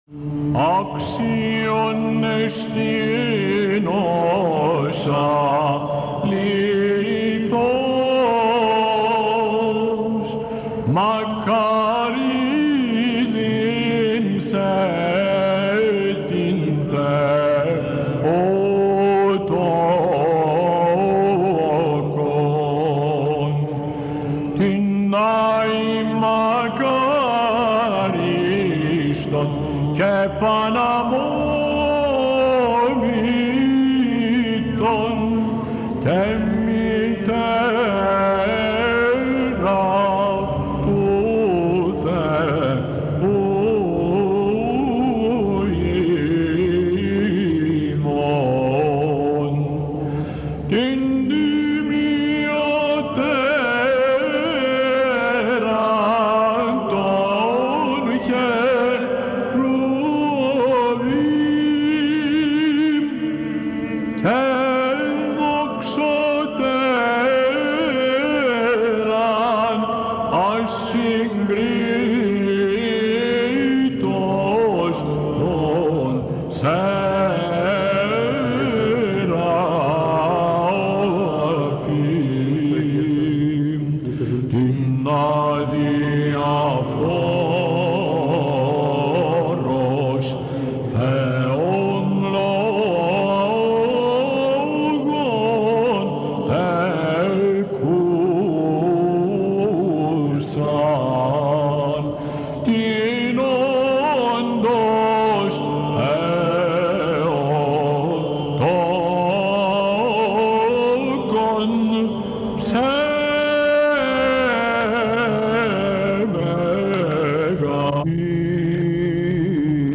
ΕΚΚΛΗΣΙΑΣΤΙΚΑ
" ΄Αξιον Εστί " - Ιούνιος 1980 Αχειροποίητος Θεσ/νίκης